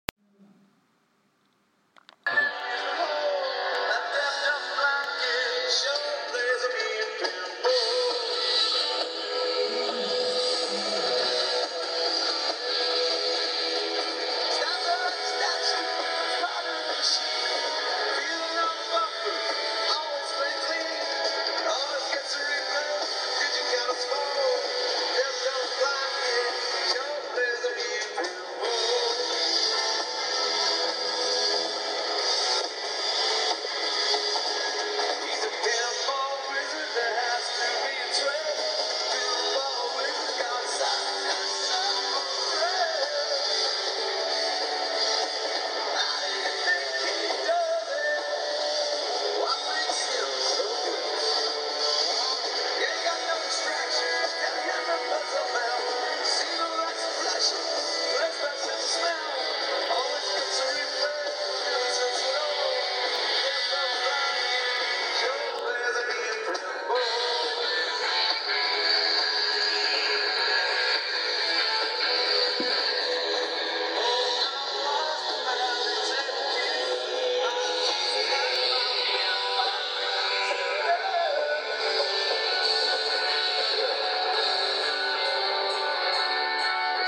Sound check at the SSE SWALEC